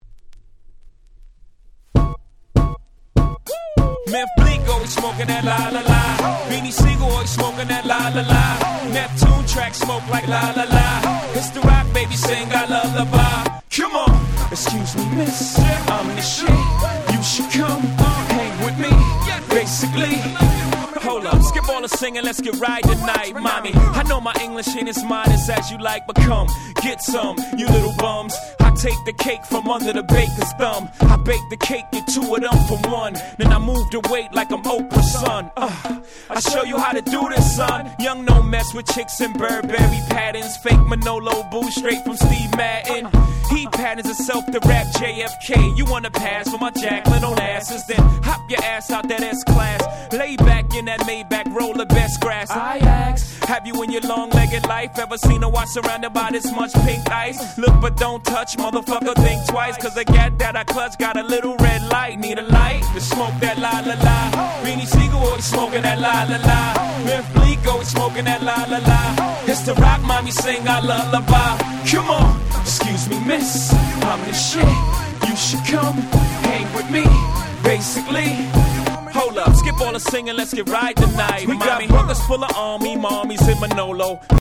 03' Smash Hit Hip Hop !!